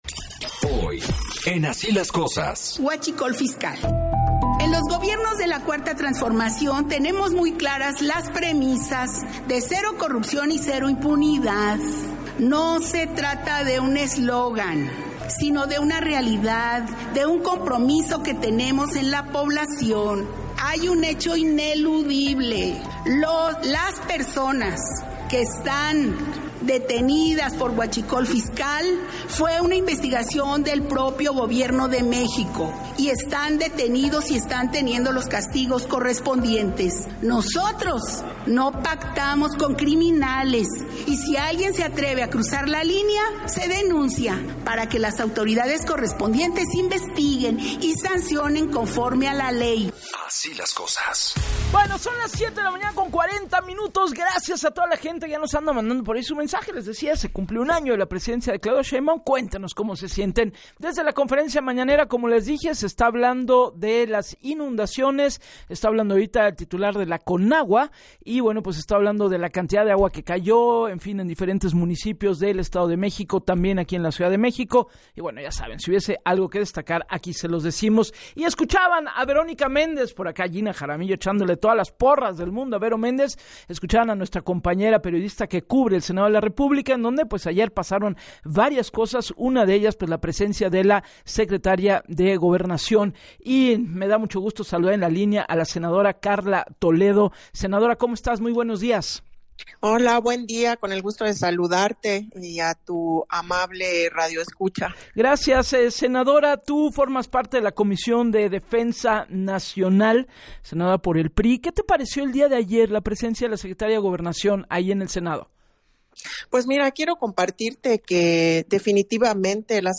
La Secretaría de Gobernación le ha quedado a deber a México en este su primer año, no ha cumplido en tener una Seguridad interior acorde a los tiempos y sobre todo, no ha defendido el Estado de derecho, los ciudadanos viven con miedo y en lo que lleva Morena en el gobierno llevamos más de 200 mil homicidios, con 96 personas asesinadas al día, señaló la senadora del PRI, Karla Toledo en entrevista con Gabriela Warkentin, para “Así las Cosas”, quien demandó “que la Segob se involucre más y deje de estar sometida al Ejecutivo”.